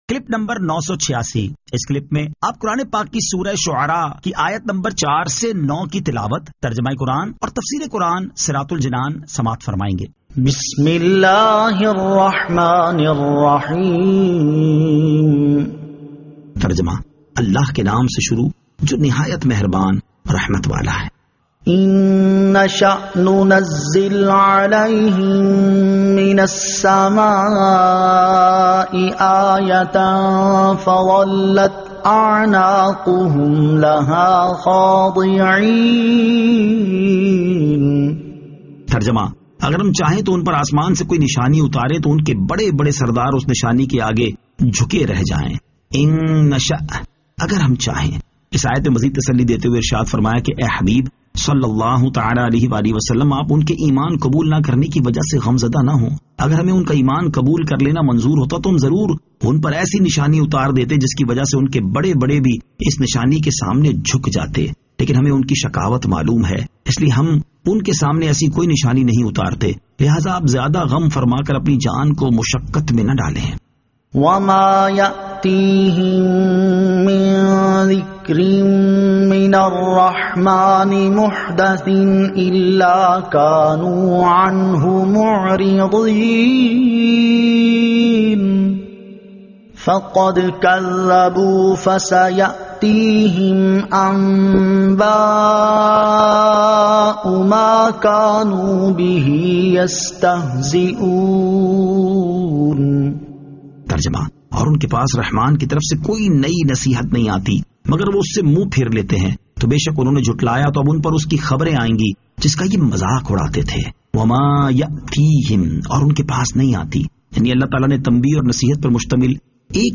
Surah Ash-Shu'ara 04 To 09 Tilawat , Tarjama , Tafseer